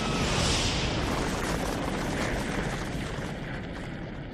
Explosion_2.wav